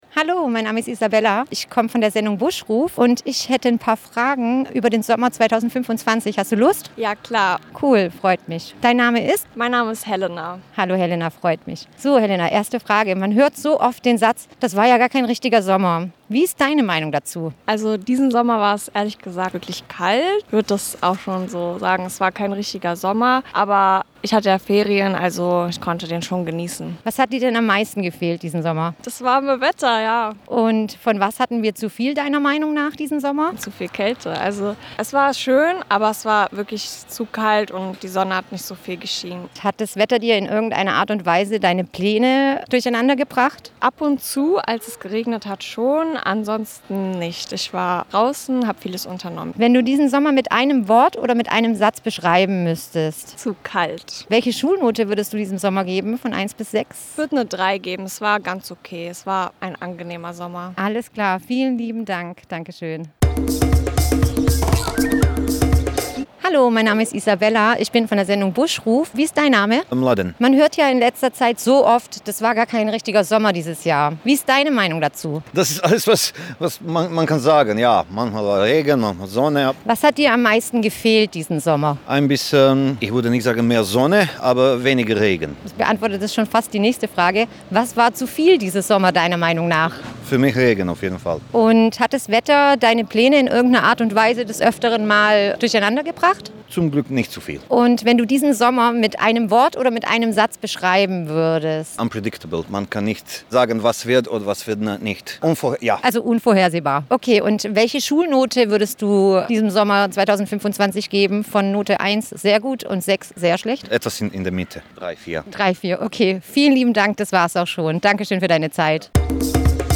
Sommer 2025 – Eine Straßenumfrage